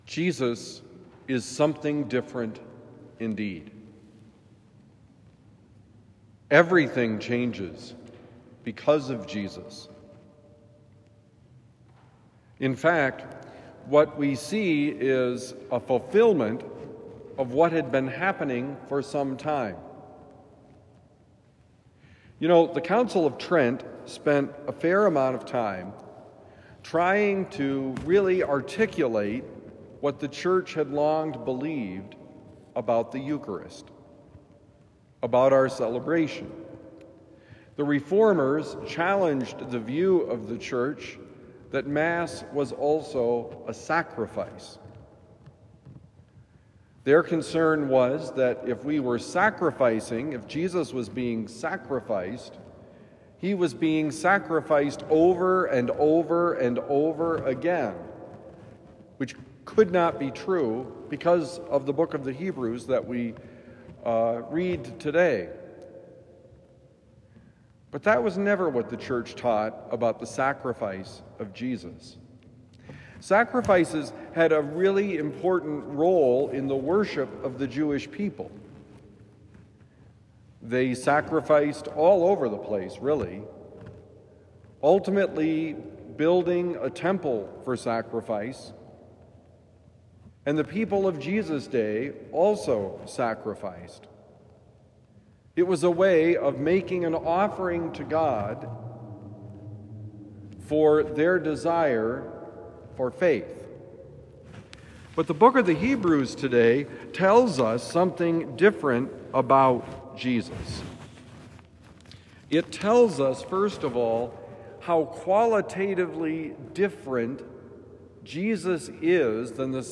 Jesus is something different indeed: Homily for Thursday, January 23, 2025